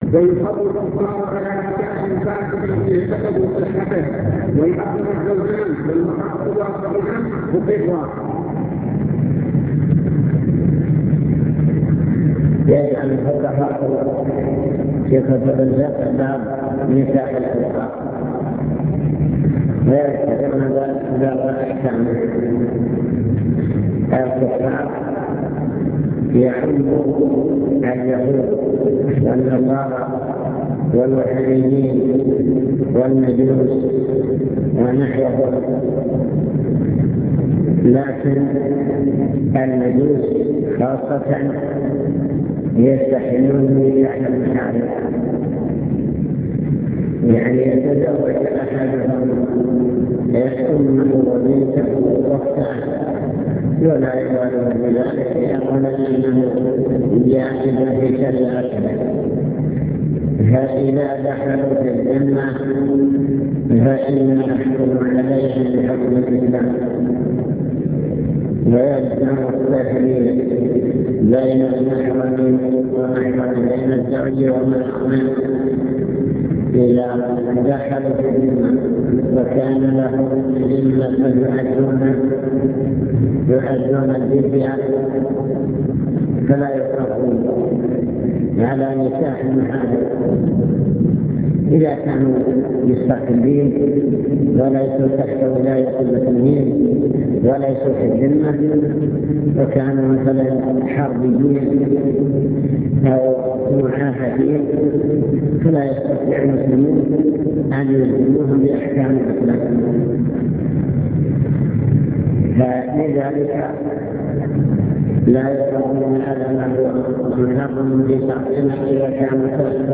المكتبة الصوتية  تسجيلات - محاضرات ودروس  محاضرات في الزواج